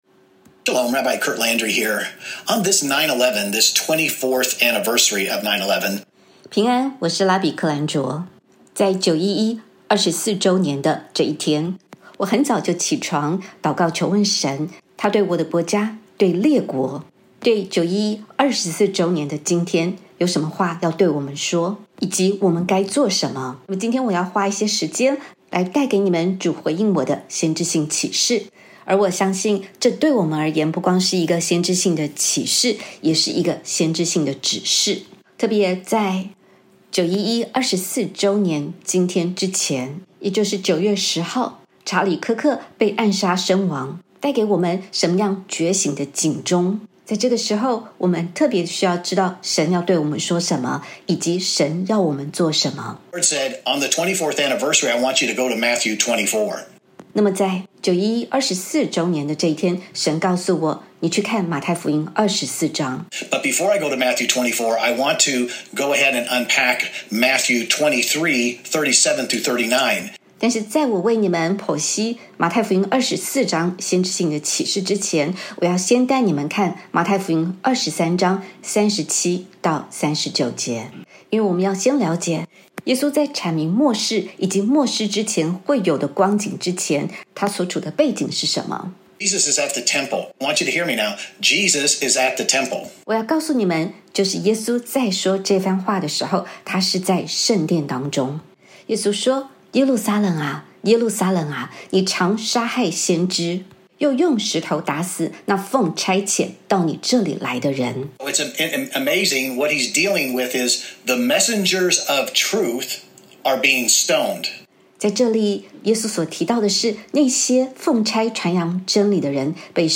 他引用民数记10:8-10 ，吹了两次号声。